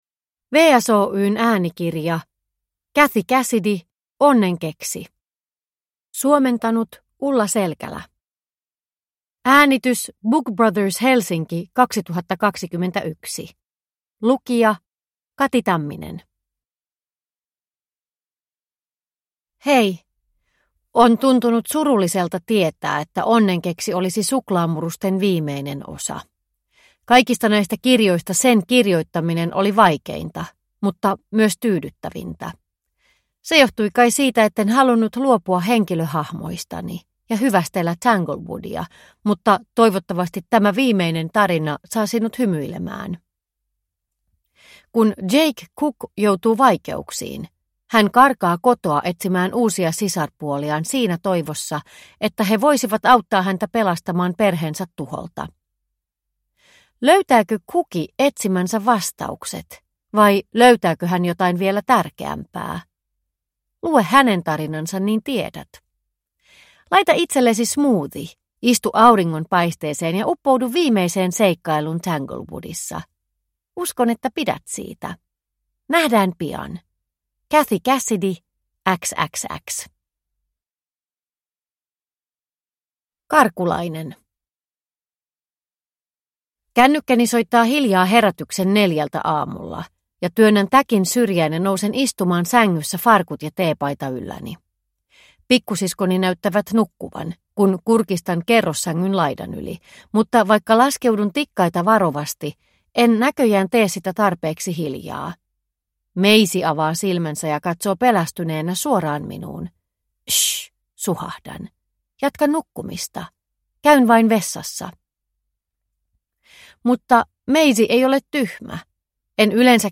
Onnenkeksi – Ljudbok